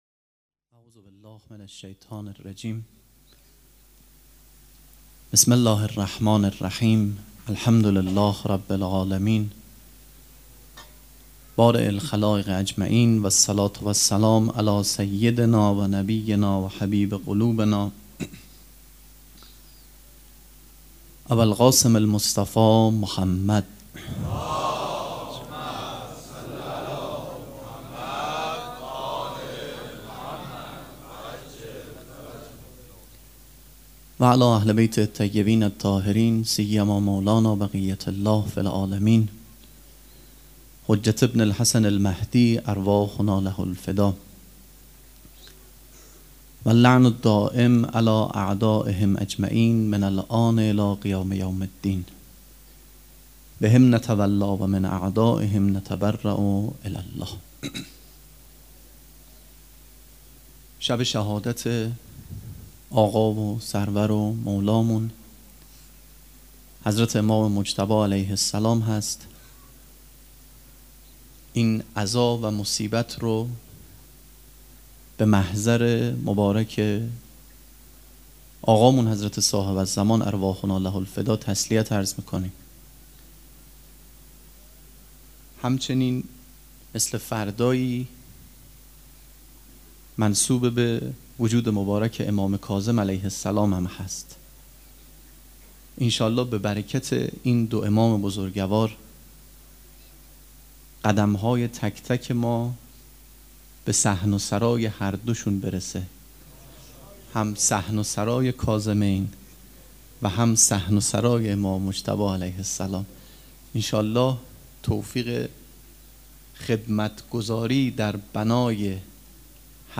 سخنرانی
مراسم عزاداری شب پنجم
sokhanrani.mp3